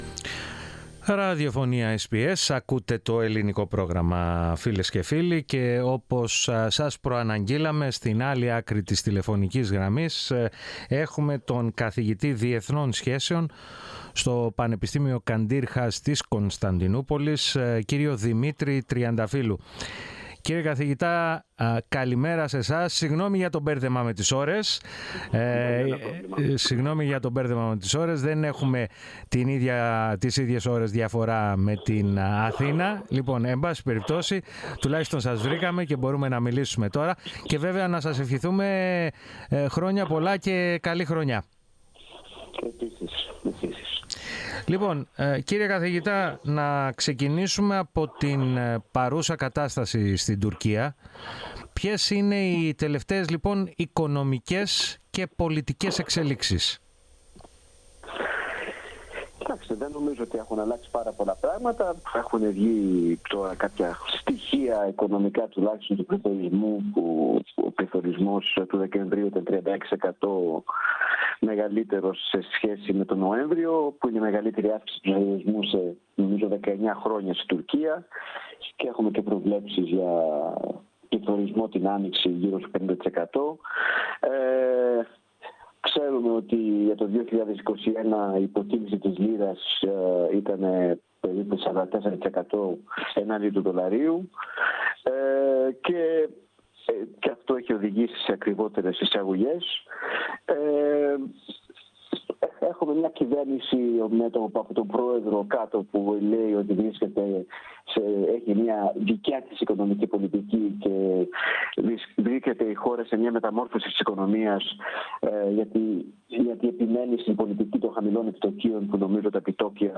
μίλησε στο Ελληνικό Πρόγραμμα της ραδιοφωνίας SBS, για τις τελευταίες οικονομικές και πολιτικές εξελίξεις στην Τουρκία, ενώ έκανε και τις προβλέψεις του για τις ελληνοτουρκικές σχέσεις το 2022.